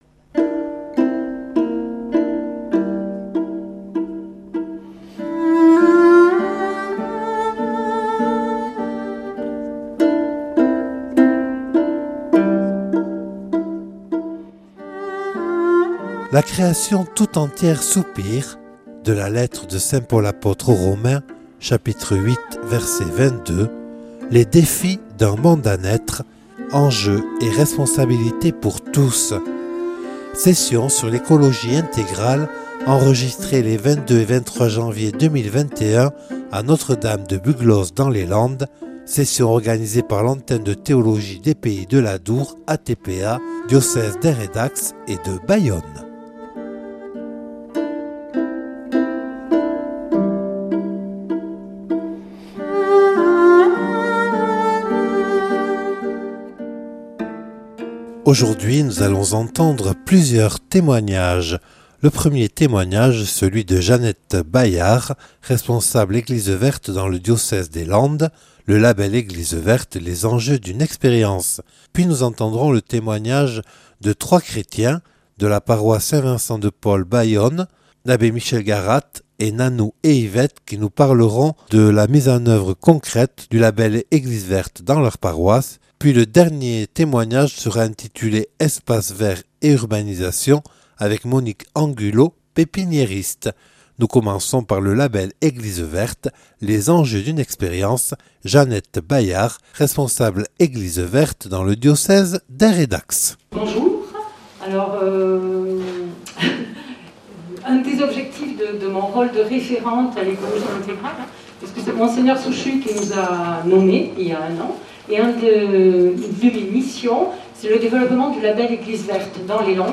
Témoignages
(Enregistré lors de la session sur l’écologie intégrale organisée les 22 et 23 janvier 2021 à Notre-Dame-de-Buglose par l’Antenne de Théologie des Pays de l’Adour).